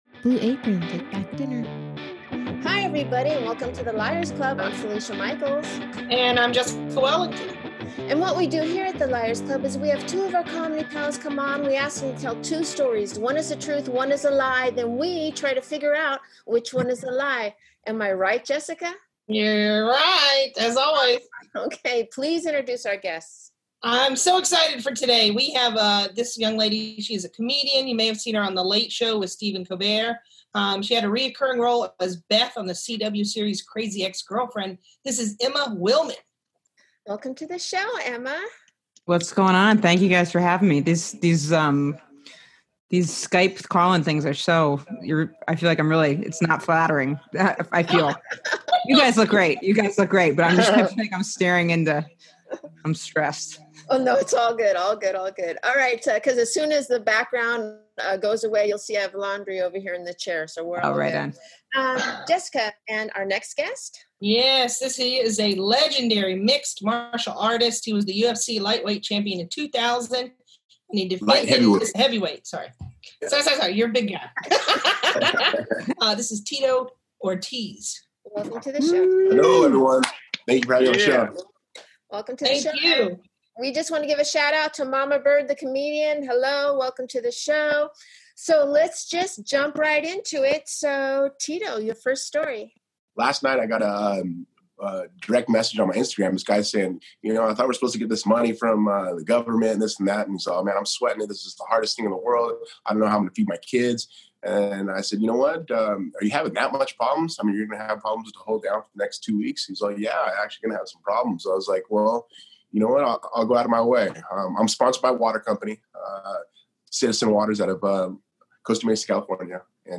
Skype/Zoom Sessions